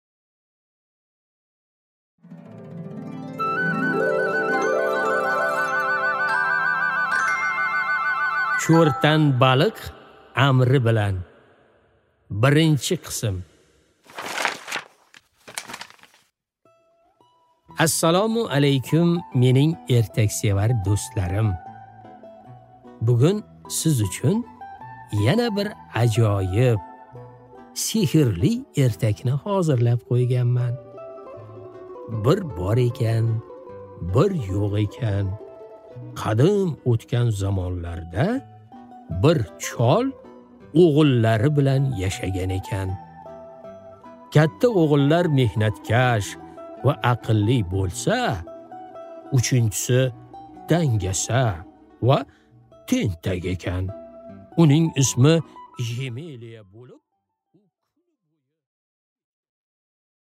Аудиокнига Cho'rtan baliq amri bilan